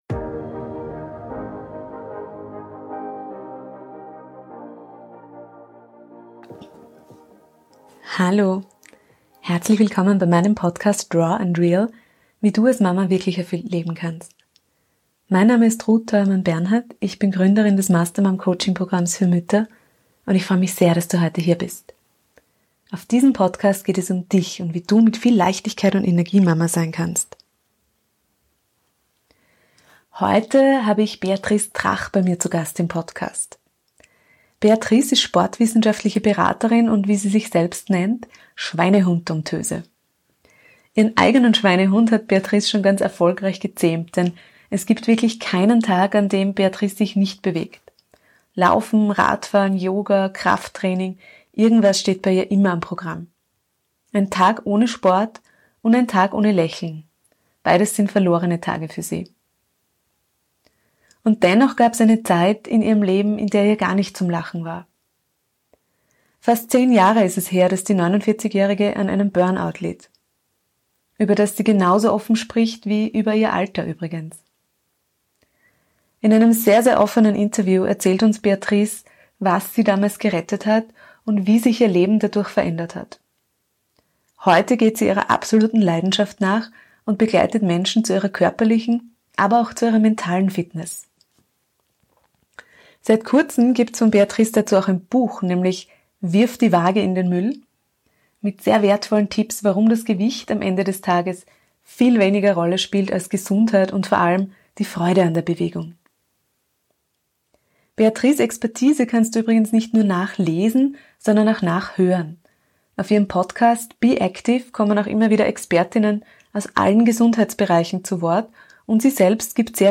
Ein sehr bewegendes Interview - im wahrsten Sinne des Wortes.